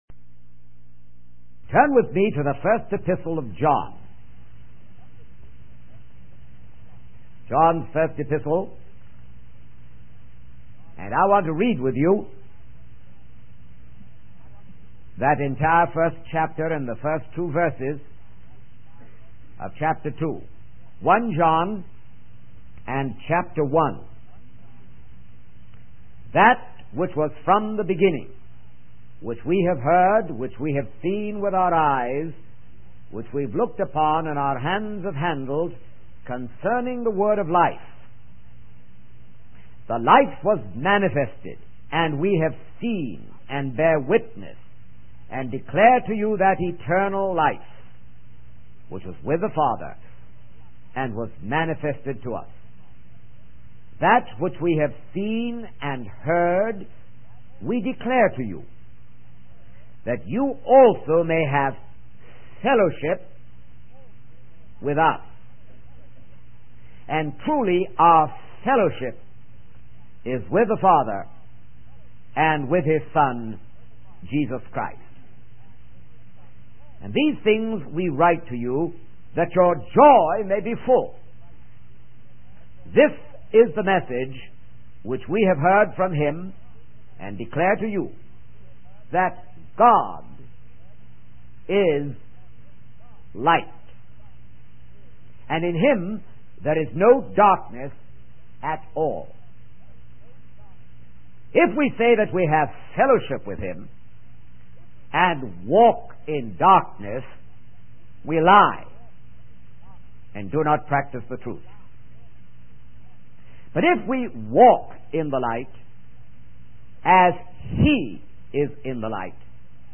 In this sermon, the preacher discusses the prescription, provision, and protection of a life of integrity. He emphasizes the importance of walking in the light, as God is light and there is no darkness in Him. The preacher uses an analogy of the sewerage system in London to illustrate how God cleanses our sins. He also addresses the crises of the Christian, including the crisis of identity and consistency.